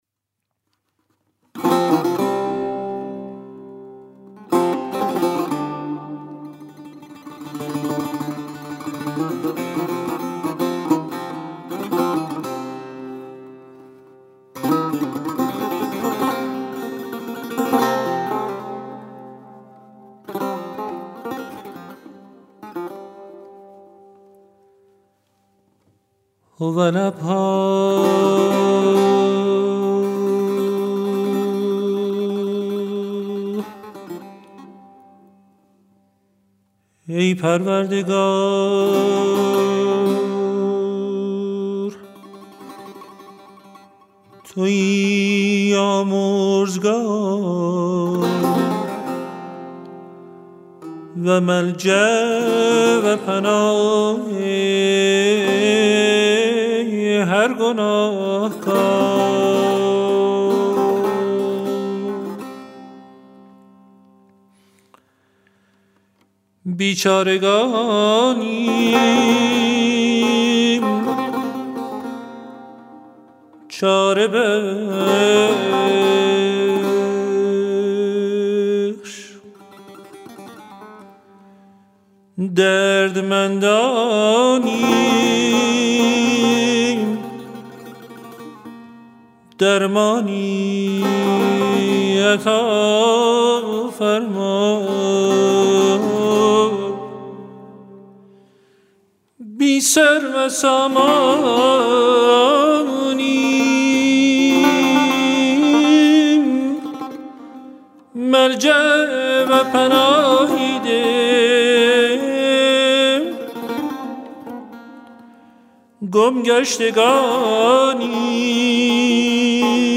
مجموعه مناجات های فارسی همراه با موسیقی